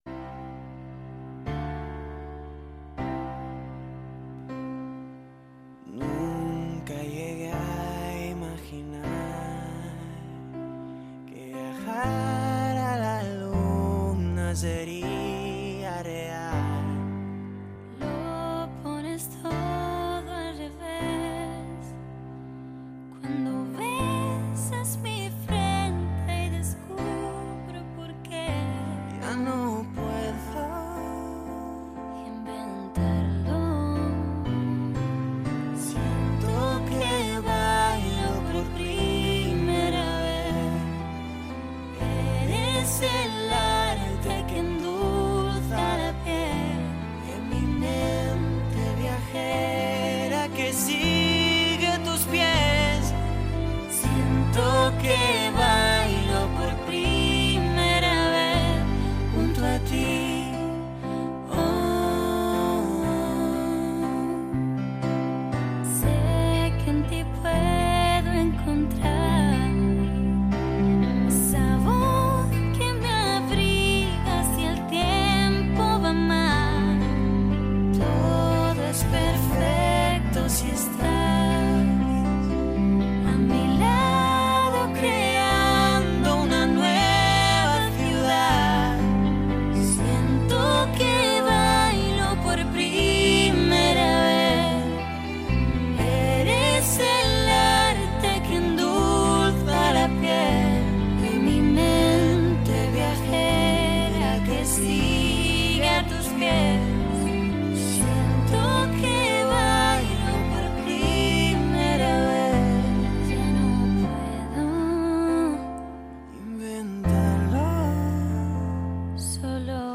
youtuberrek zuzenean abestu dute Faktorian